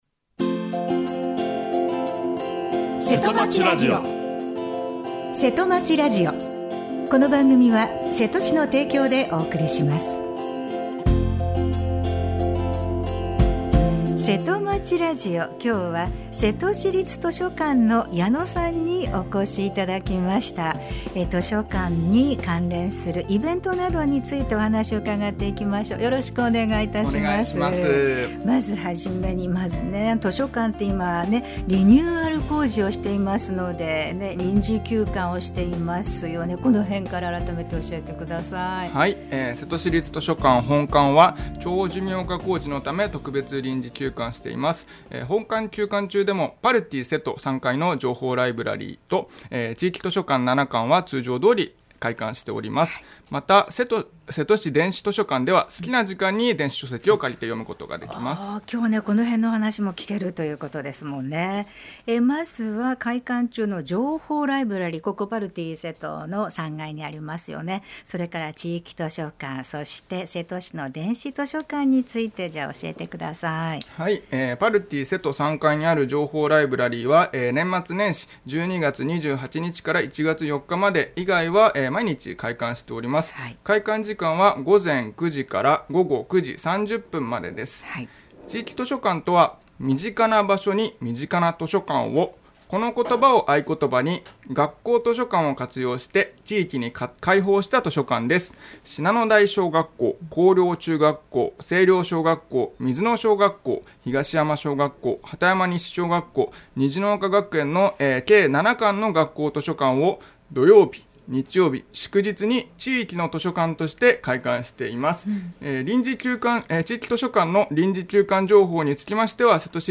生放送 | radiosanq-hp | 2025年4月17日 10:43 AM